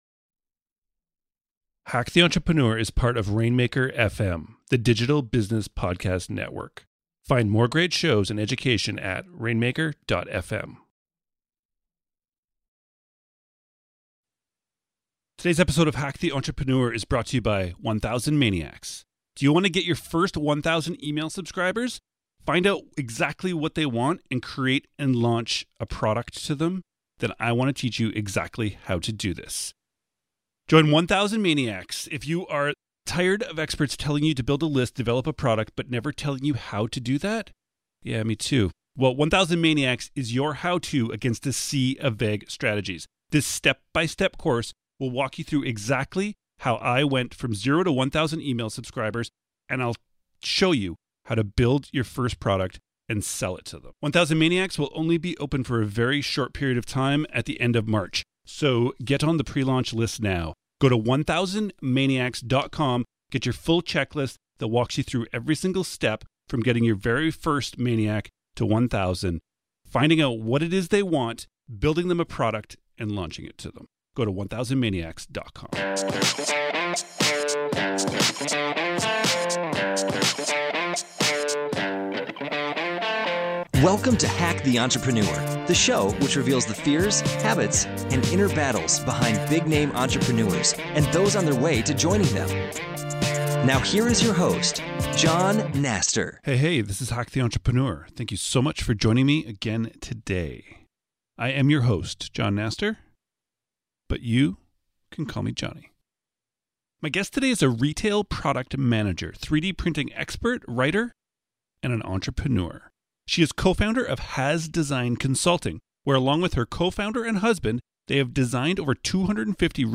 My guest today is a retail product designer, 3D printing expert, writer, and an entrepreneur.